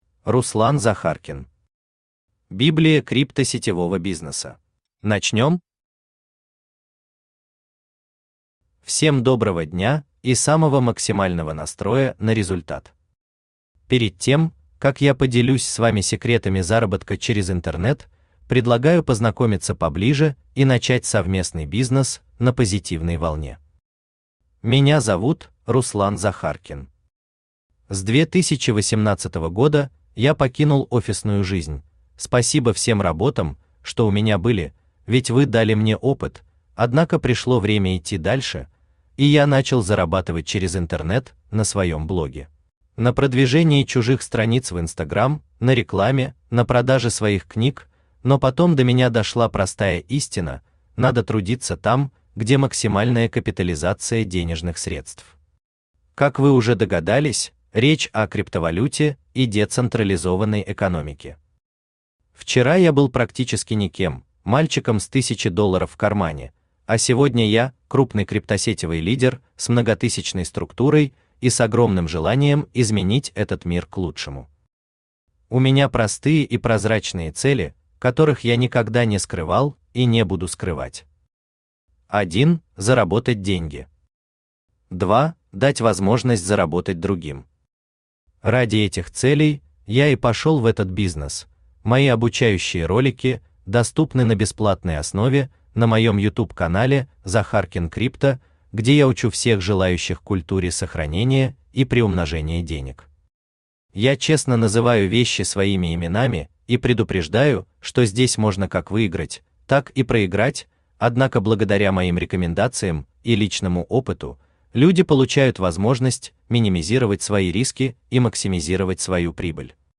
Аудиокнига Библия крипто-сетевого бизнеса | Библиотека аудиокниг
Читает аудиокнигу Авточтец ЛитРес.